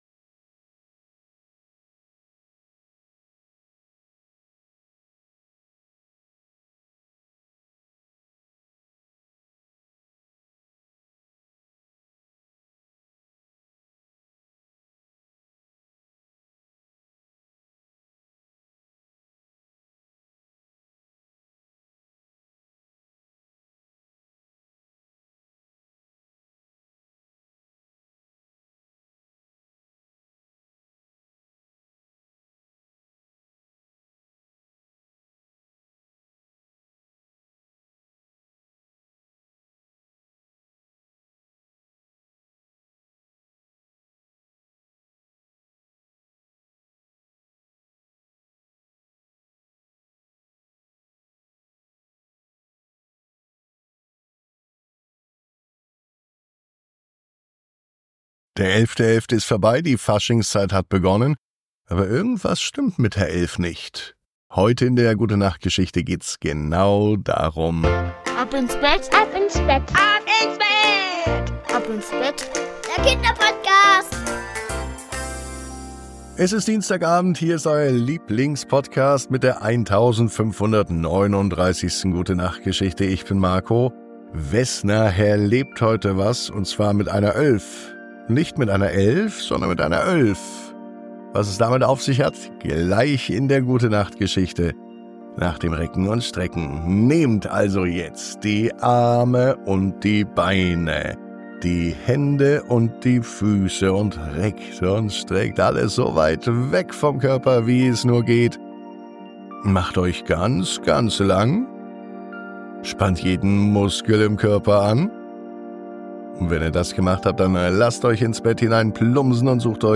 Während Vesna neugierig zuschaut, entdeckt sie die Geheimnisse hinter den scheinbar gleichen Zahlen. Schließlich hilft sie ihnen, ihre Unterschiede zu feiern und gemeinsam etwas Besonderes daraus zu machen – den 11.11.! Eine wundervolle Gute-Nacht-Geschichte voller Spaß und Harmonie, perfekt zum Träumen und Einschlafen.